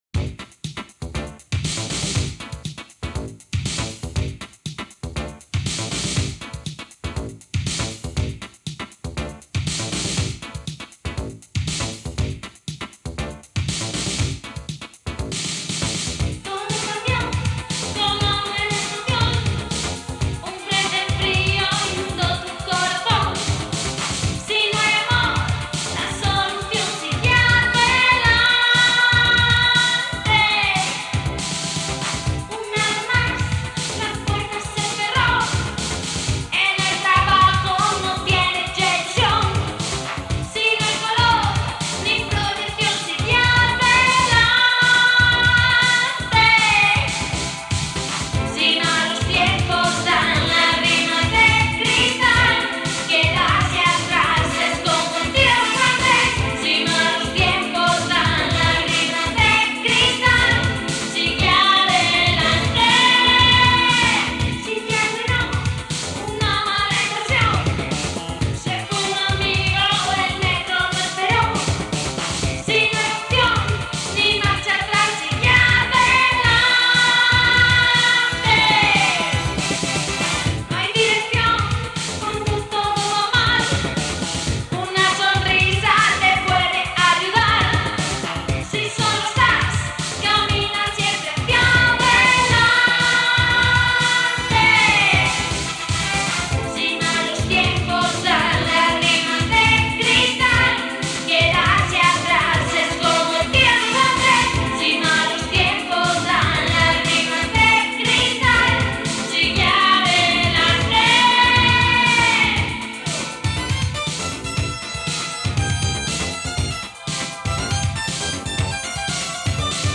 pero su voz era muy "agresiva" muy Rockera. Además, su imagen en foto era también transgresora, así que los temas tenían algo de mujer fatal, mezcla de Funky y Rock.